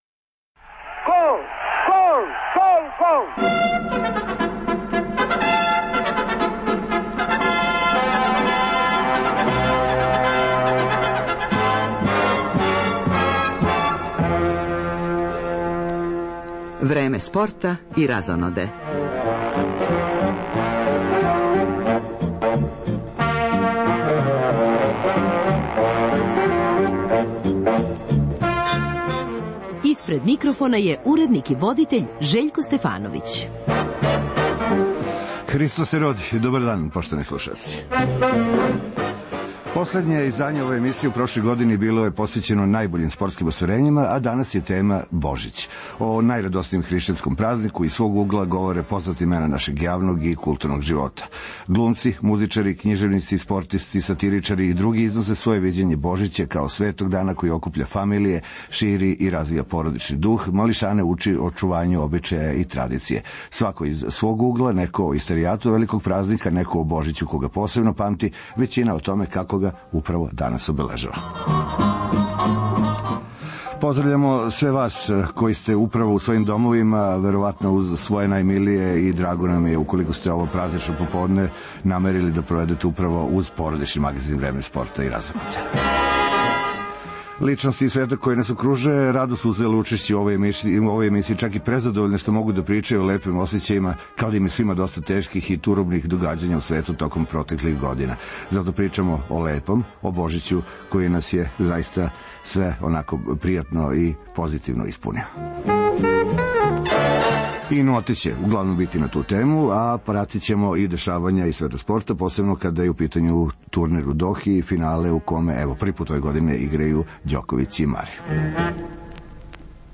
Глумци, музичари, књижевници, спортисти, сатиричари и друге износе своје виђење Божића, као светог дана који окупља фамилије, шири и развија породични дух, малишане учи очувању обичаја и традиције.